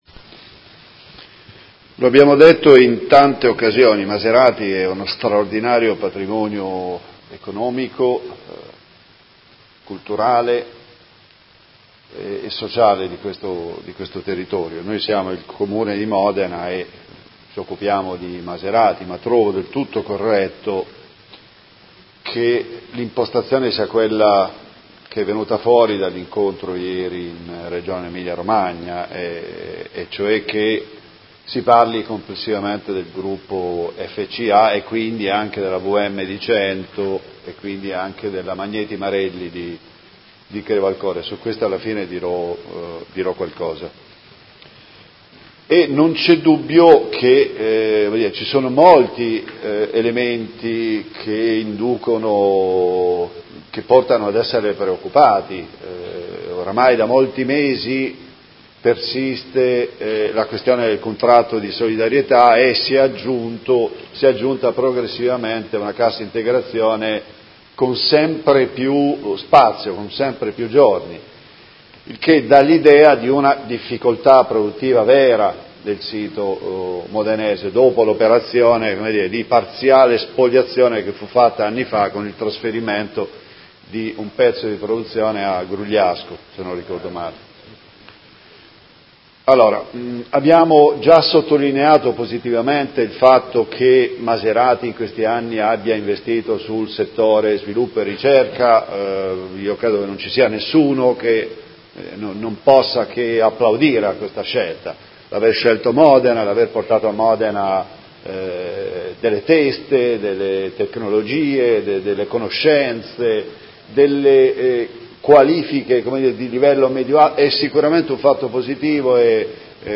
Seduta del 15/11/2018 Interrogazione dei Consiglieri Malferrari e Trande (Art1-MDP/Per Me Modena) avente per oggetto: Quale futuro si prospetta per lo stabilimento storico Maserati di Modena.